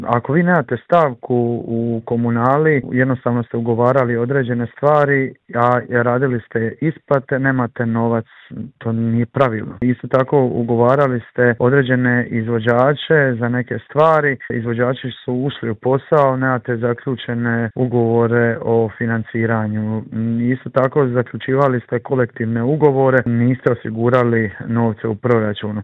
U Intervju Media servisa razgovarali smo s gradonačelnikom Splita Tomislavom Šutom koji nam je prokomentirao aktualnu situaciju i otkrio je li spreman za eventualne izvanredne izbore.